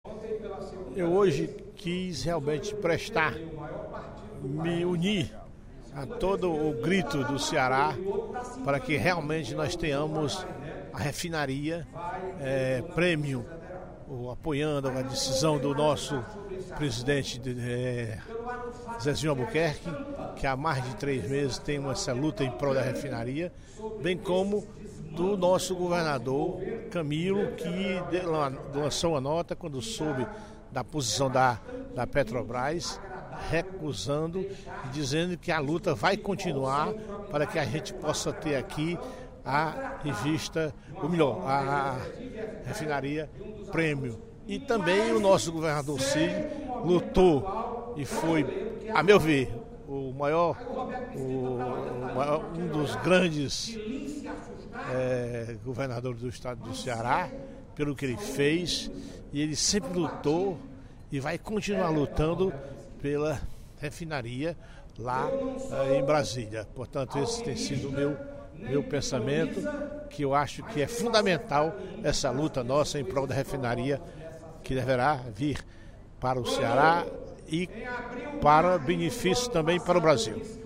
O deputado Professor Teodoro (PSD) abriu o primeiro expediente da sessão plenária desta sexta-feira (06/02) ressaltando a importância da refinaria Premium II para o Ceará. O parlamentar argumentou que uma federação deve distribuir de maneira harmoniosa e equitativa seus bens para que o País se desenvolva.